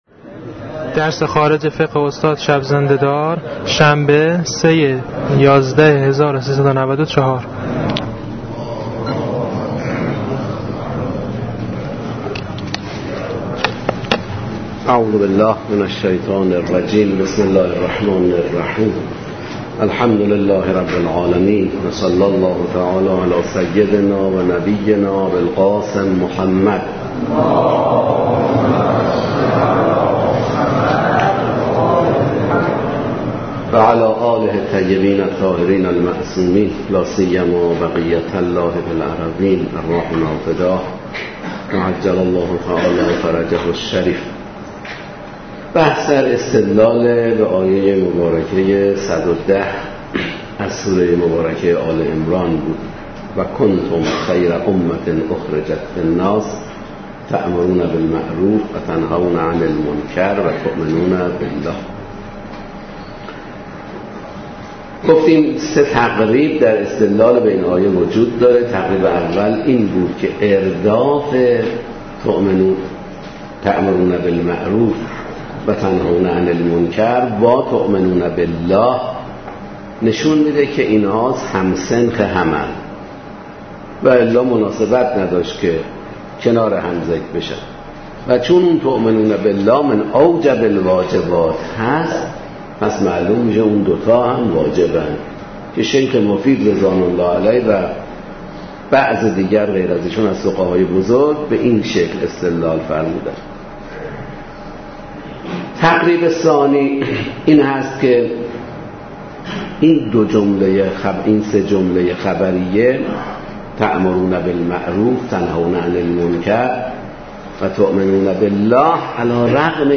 لازم به ذکر است: متن ذیل پیاده شده از صوت درس می‌باشد و هیچگونه ویرایشی روی آن اعمال نشده است.